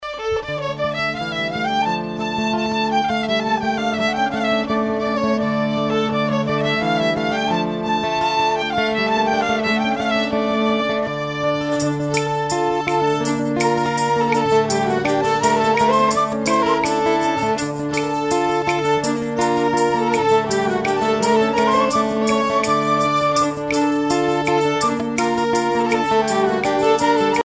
danse : scottich trois pas
Recherches pour l'enseignement de la musique traditionnelle